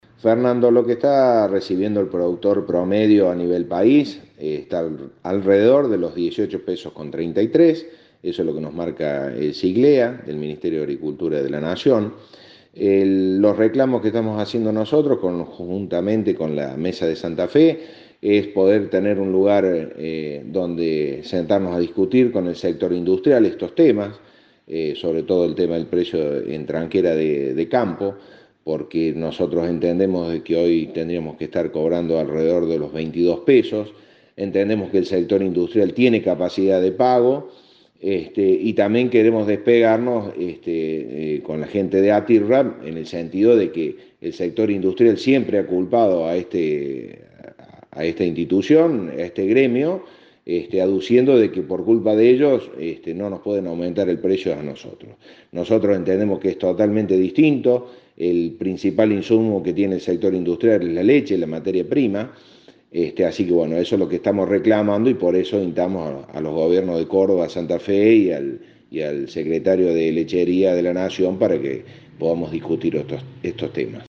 El entrevistado indicó que la industria láctea tiene capacidad de pago y que el valor del litro de leche que se le abona al productor debería estar en los 22 pesos.